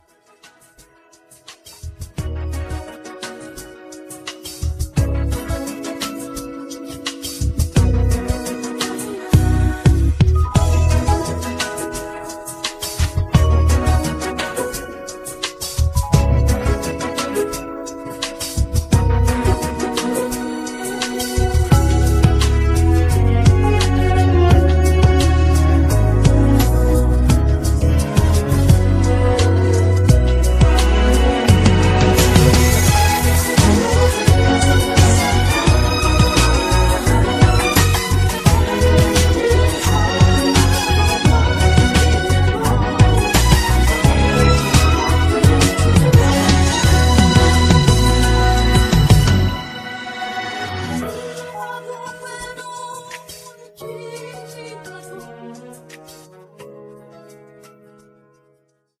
음정 -1키 2:50
장르 가요 구분 Voice MR